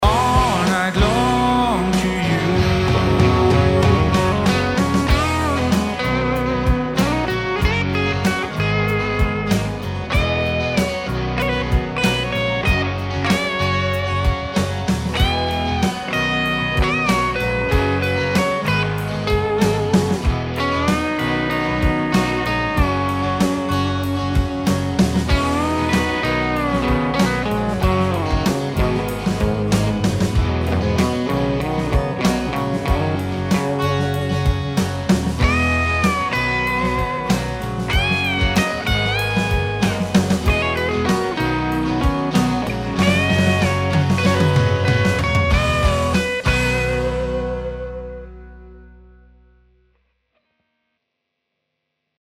[New American Country] - coming home - Mix
Ich hab' just for fun nochmal über das komplette Ende rumgegniedelt.